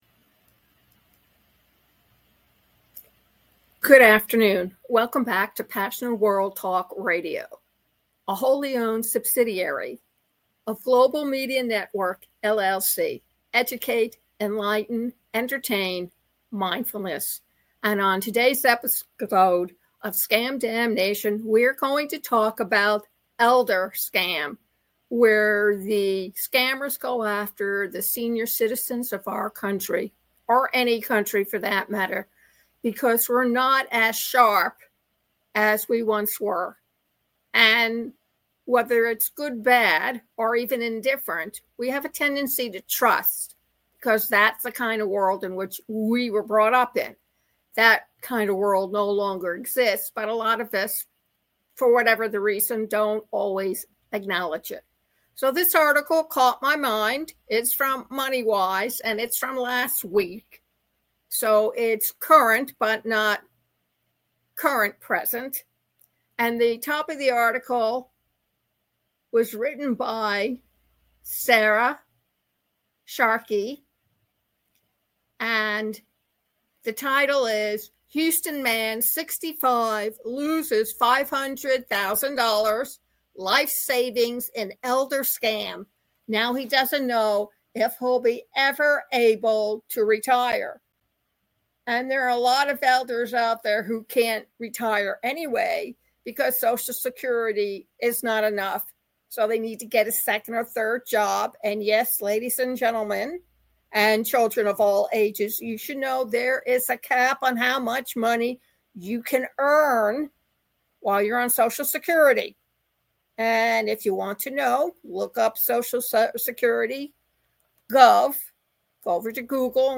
audio commentaey